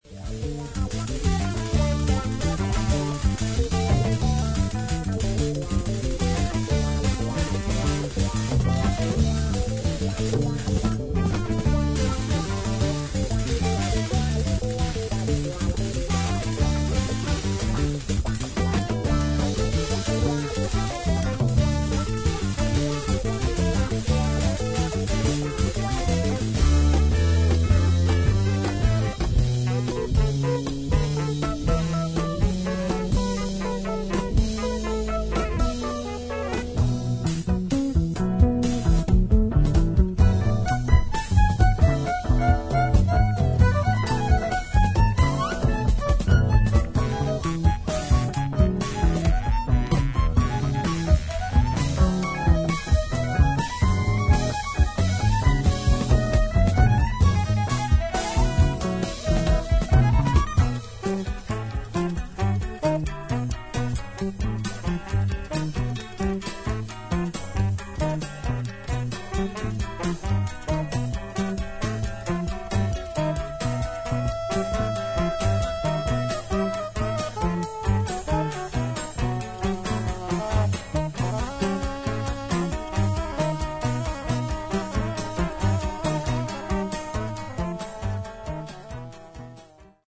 ジャズやプログレッシブロックの要素に加え、スコア中にインプロヴィゼーションも織り交ざるイギリスの前衛ロック名盤